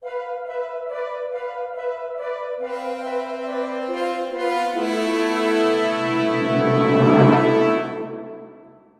描述：与Fl Studio制作，史诗般的电影情况。
标签： 140 bpm Cinematic Loops Strings Loops 1.51 MB wav Key : Unknown
声道立体声